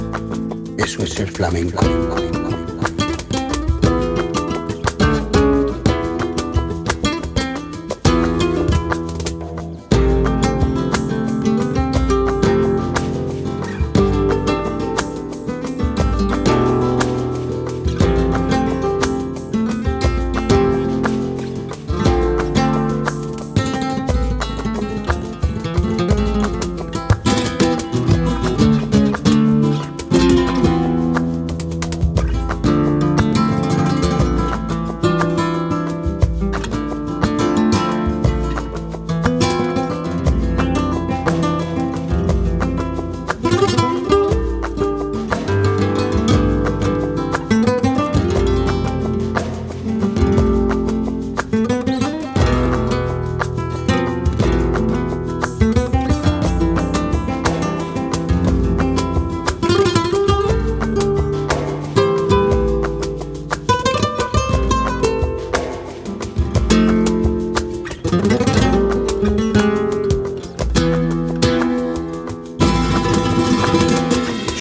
Zurück zu: Flamenco
Fandango de Huelva 3:36